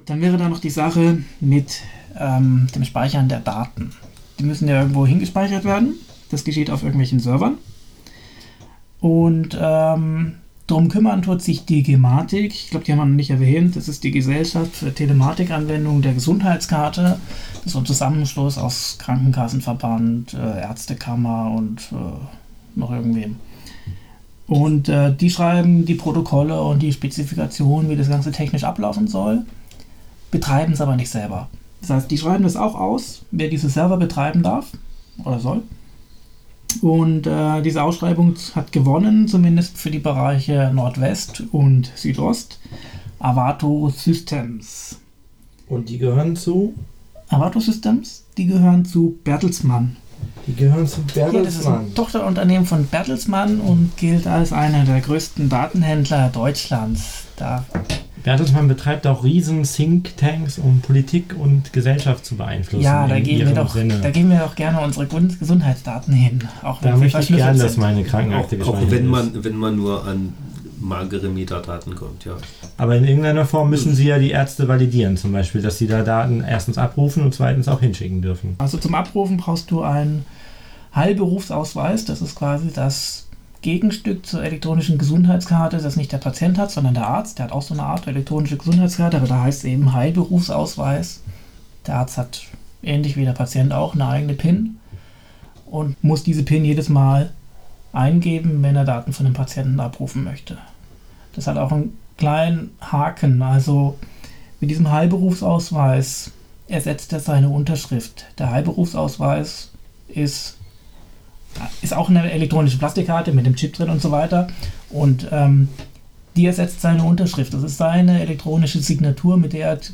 Das Interview gekürzt : Teil 1 17:56 Teil 2 13:49Das Interview ungekürzt : 61:38